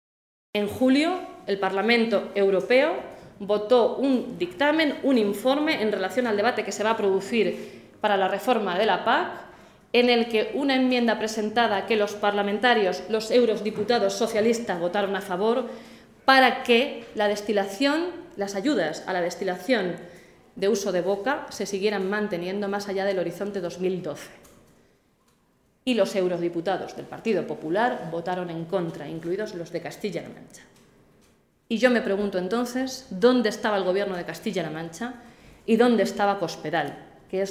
Blanca Fernández, diputada regional del PSOE de C-LM
Cortes de audio de la rueda de prensa